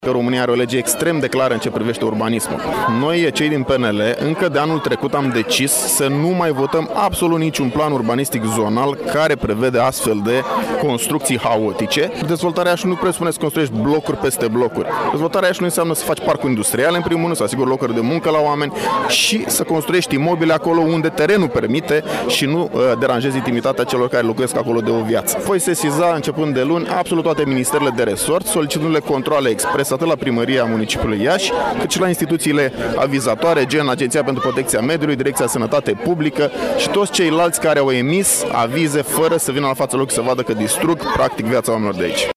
La acțiune au fost prezneți și câțiva membri PNL, între care deputatul Marius Bodea și consilierul local, Etienne Ignat.
Marius Bodea a declarat că va sesiza, începând de luni, toate ministerele care au în competențe rezolvarea unor astfel de probleme, și va solicita controale la Primăria Iași, dar și la alte instituții care au eliberat avizele pentru proiectul din zona Oancea și din alte zone ale orașului, pentru a fi verificat modul în care au fost emise aceste avize.